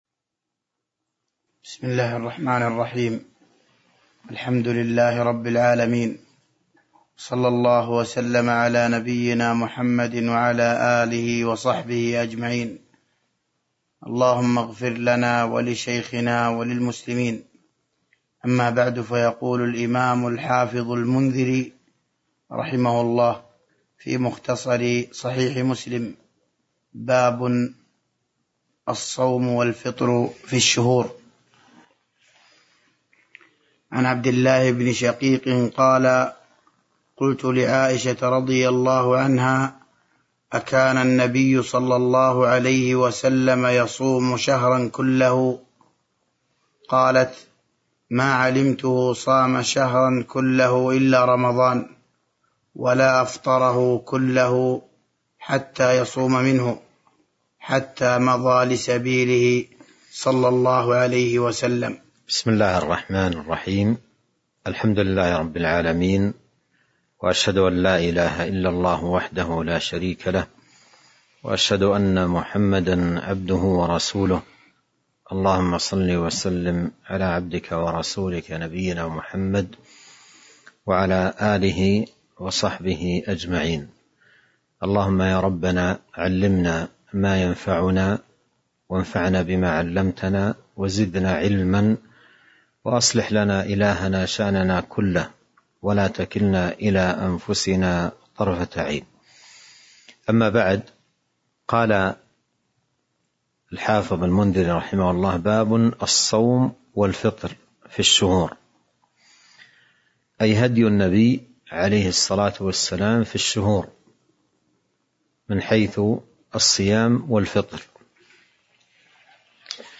تاريخ النشر ١٢ شعبان ١٤٤٢ هـ المكان: المسجد النبوي الشيخ